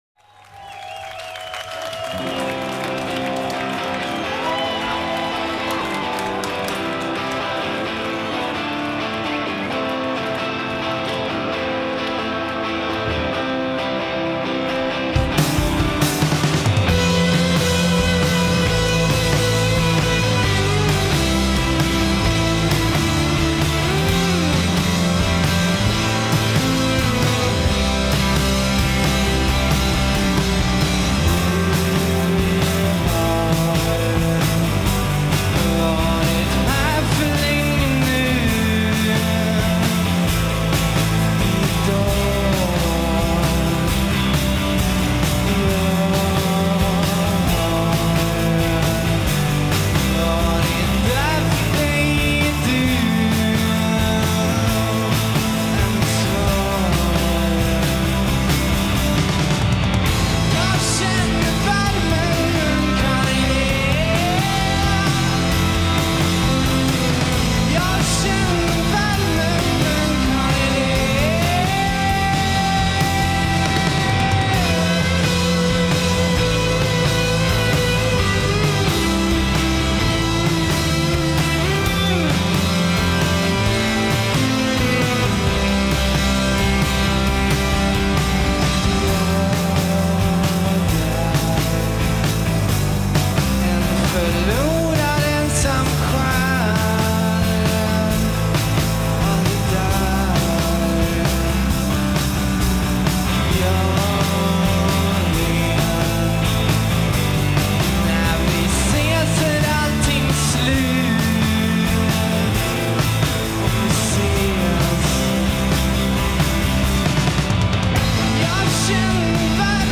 Swedish Alt/Indie band